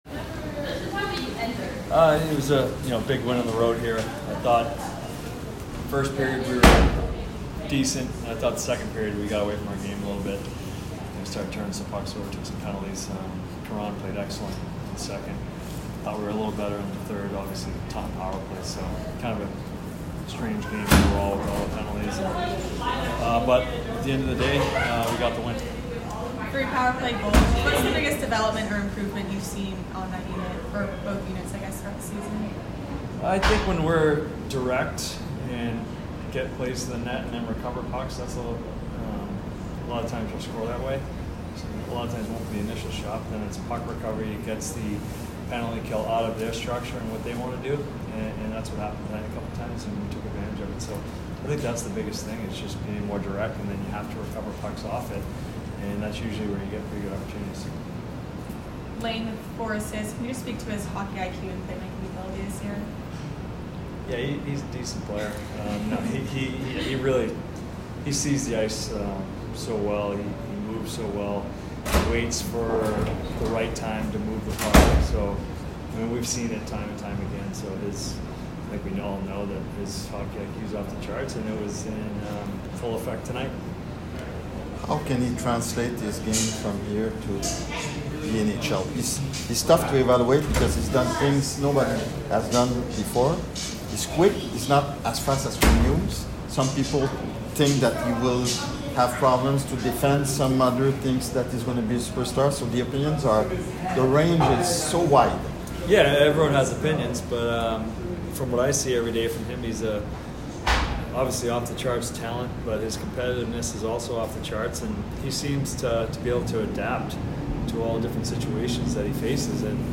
Vermont Postgame Interview